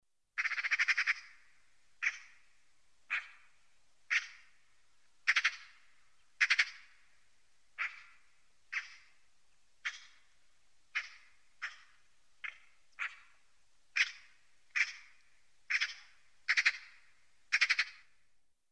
Вы можете слушать онлайн или скачать в формате mp3 её характерное стрекотание, карканье и другие варианты криков.
Наглый крик сороки в природе